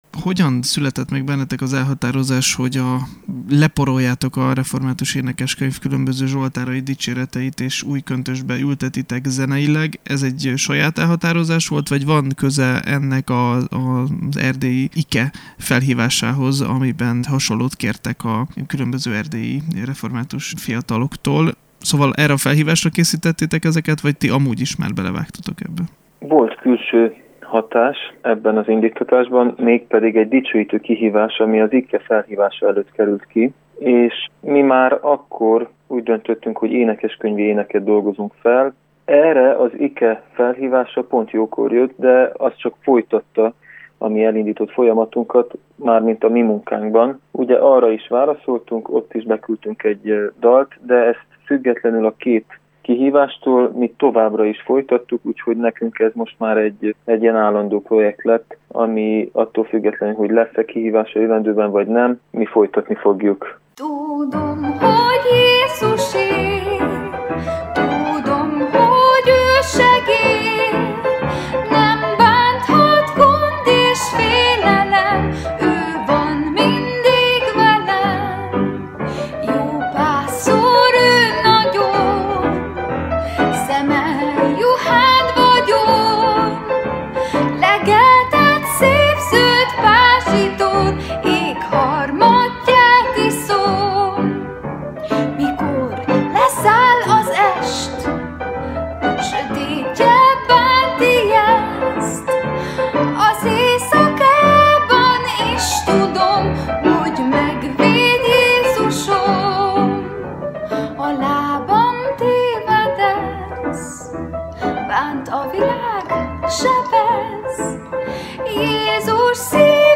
Ragtime stílusban dolgozza fel a református énekeskönyv dicséreteit, zsoltárait egy kolozsvári duó.